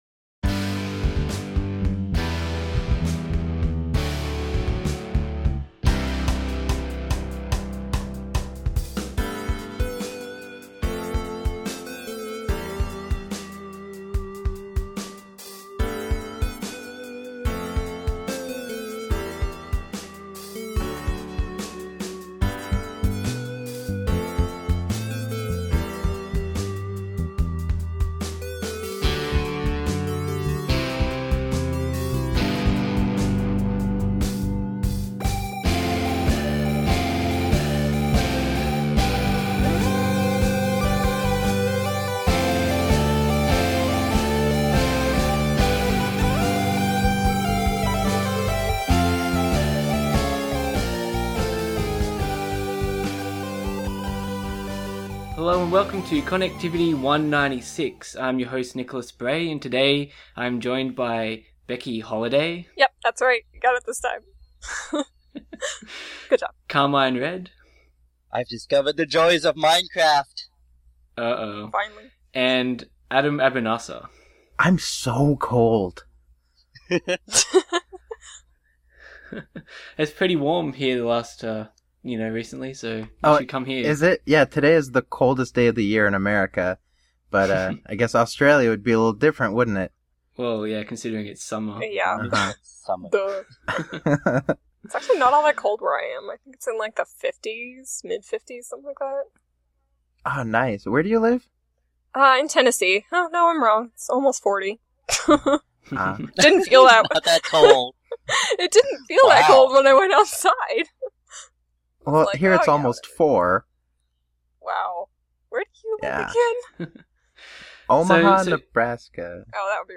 The Connectivity gang chat about the possibilities for what 2016 may hold.